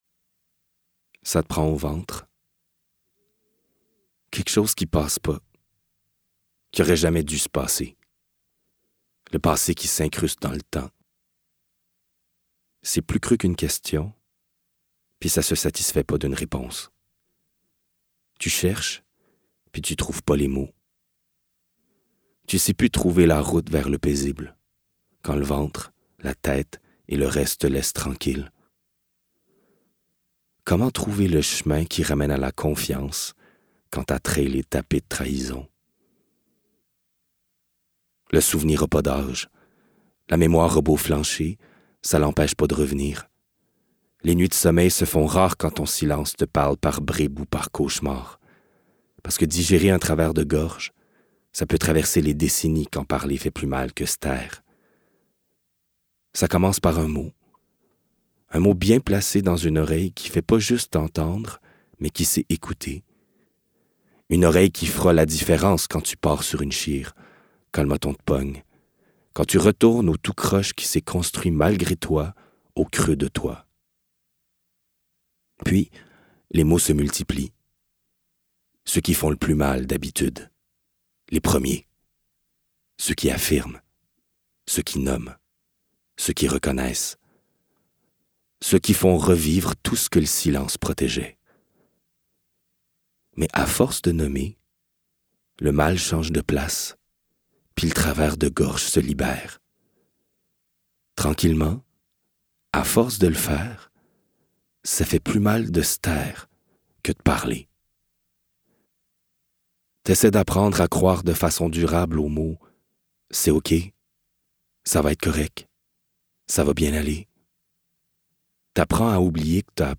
In the audio version, the stories in Tracer les maux come to life through the voice of their author or a chosen narrator.